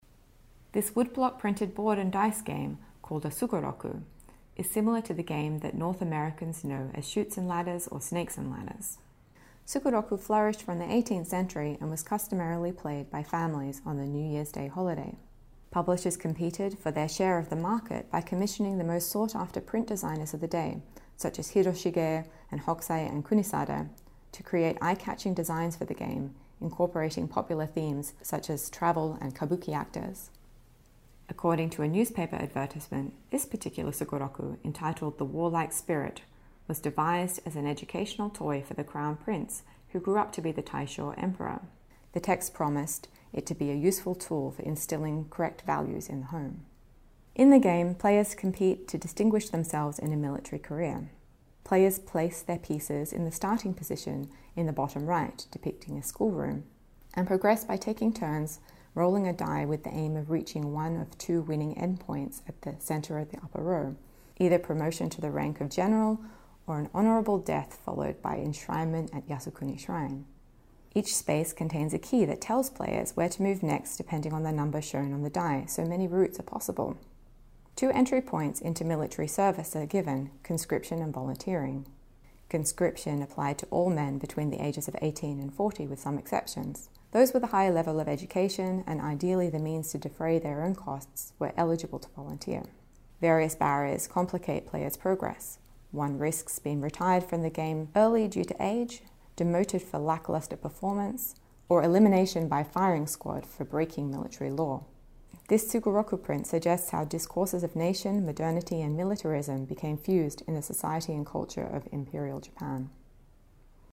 This audio guide features an introduction in both English and Chinese, and expert commentary on 8 works of art from the exhibition.